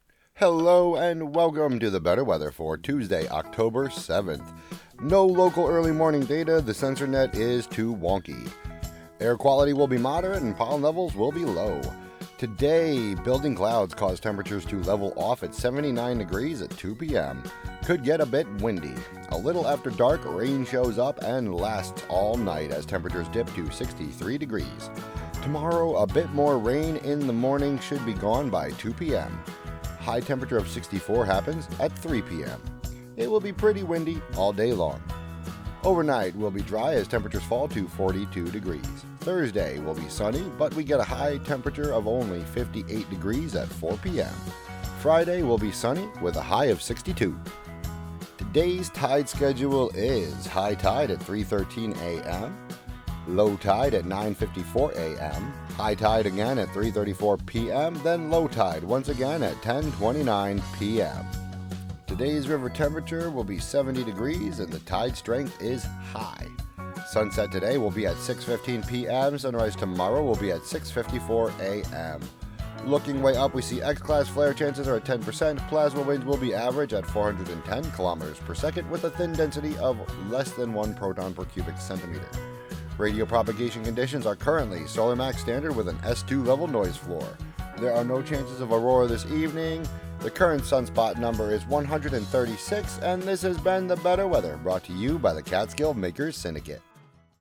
brings listeners meteorological predictions, pollen counts, Hudson River water temperatures, space weather, and more on WGXC 90.7-FM.